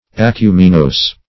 Acuminose \A*cu"mi*nose`\